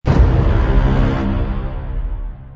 Download Dramatic sound effect for free.
Dramatic